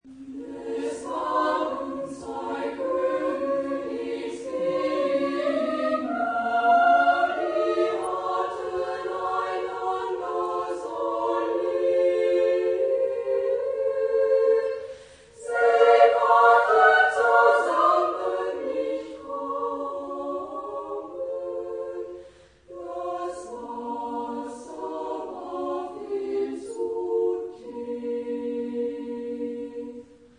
... Chanson populaire de Suède ...
Genre-Style-Form: Secular ; Lied ; Polyphony
Mood of the piece: narrative ; sorrowful ; flowing
Type of Choir: SSA  (3 women voices )
Tonality: A minor
Discographic ref. : 3.Deutscher Chorwettbewerb, 1990